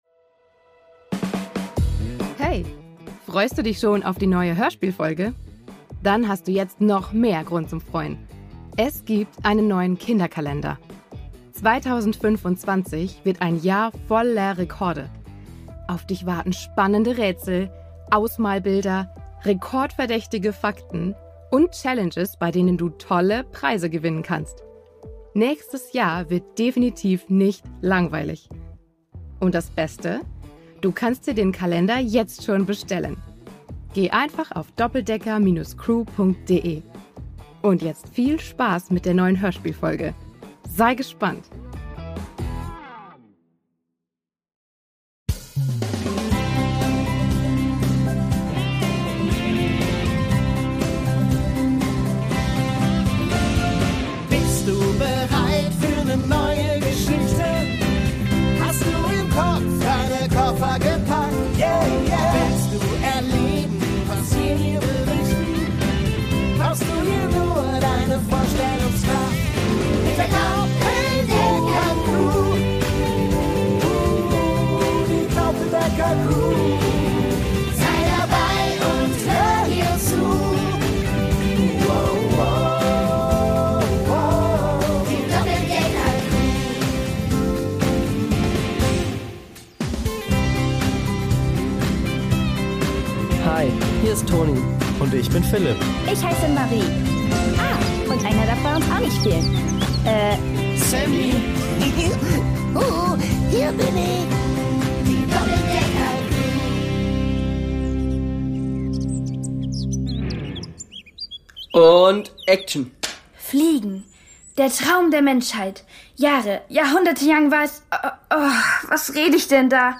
USA 2: Betrug im Museum (1/3) | Die Doppeldecker Crew | Hörspiel für Kinder (Hörbuch) ~ Die Doppeldecker Crew | Hörspiel für Kinder (Hörbuch) Podcast